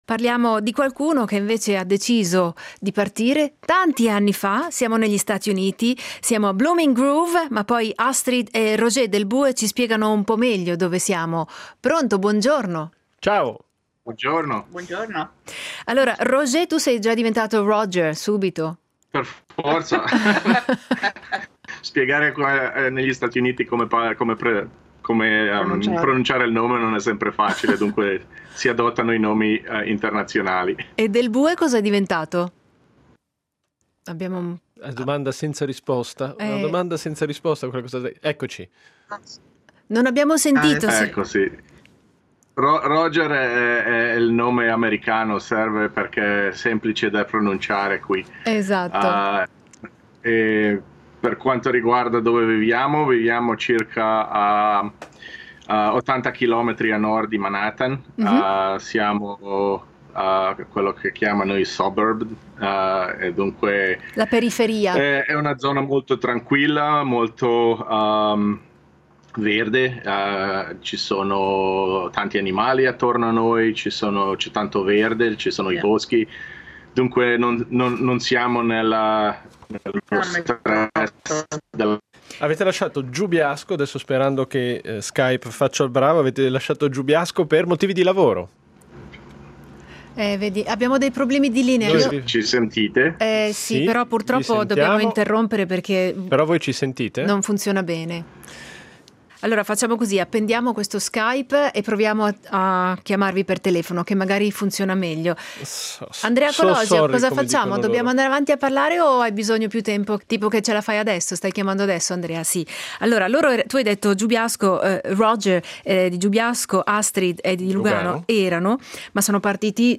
Li abbiamo incontrati a Blooming Grove, una piccola cittadina a 80km a nord di Manhattan.